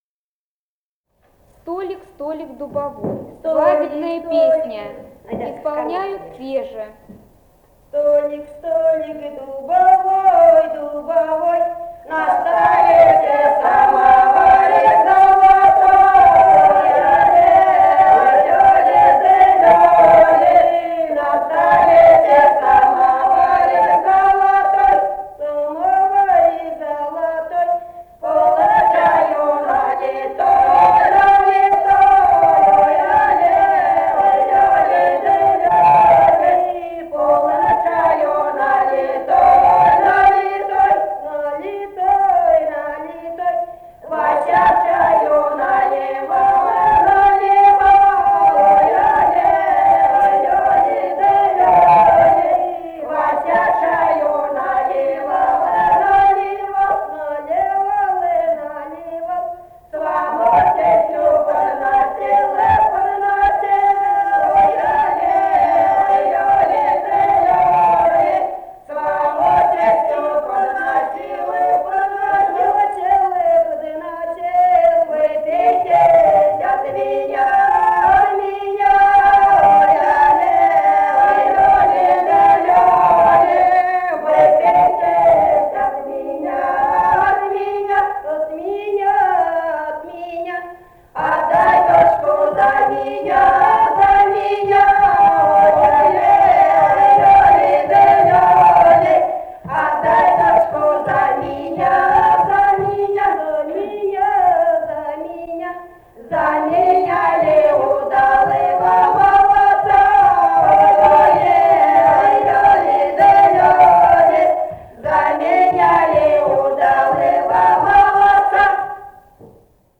Этномузыкологические исследования и полевые материалы
«Столик, столик дубовой» (свадебная).
Самарская область, с. Виловатое Богатовского района, 1972 г. И1316-04